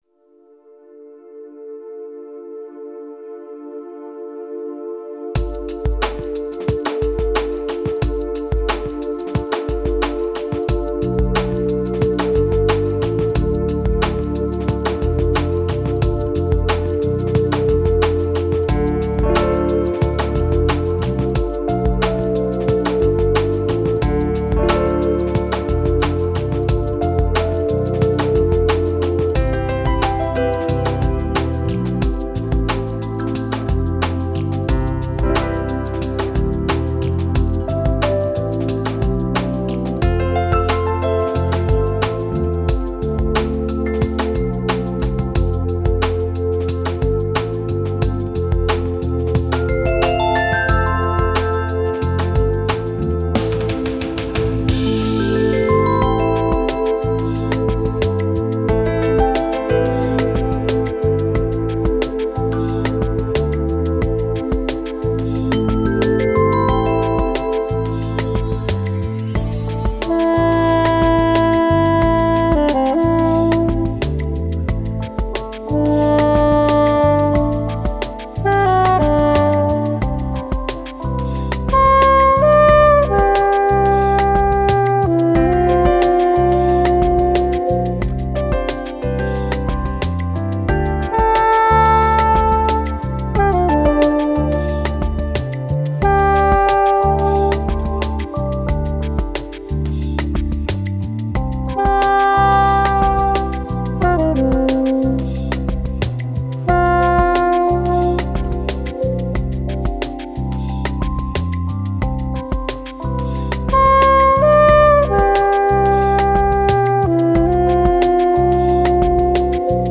ορχηστρικές συνθέσεις
Lounge & Calm διάθεση